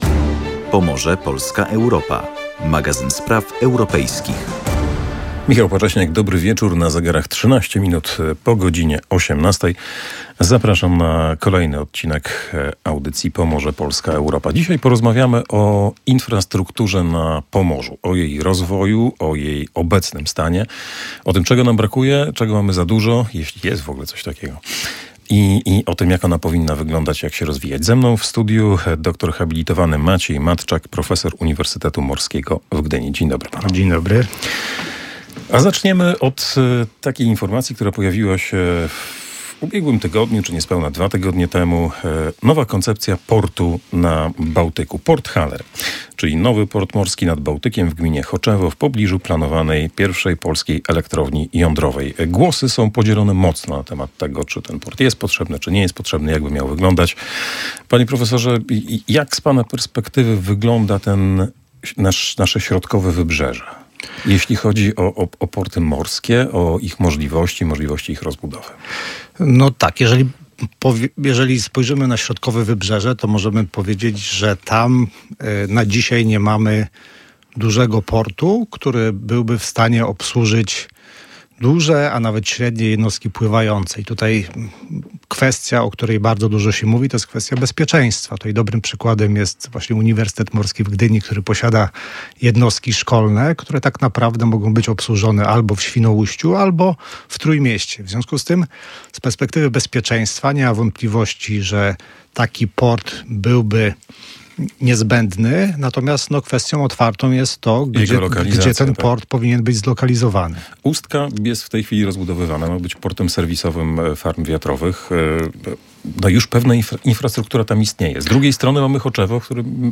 Ekspert analizował również potencjał rozwojowy portów w Gdańsku i Gdyni, wskazując na konieczność pilnej rozbudowy infrastruktury dostępowej – drogowej i kolejowej. Rozmowa dotyczyła także ogromnego wpływu gospodarki morskiej na finanse publiczne oraz wyzwań rynku pracy w obliczu rozwoju morskiej energetyki wiatrowej.